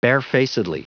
Prononciation du mot barefacedly en anglais (fichier audio)
Prononciation du mot : barefacedly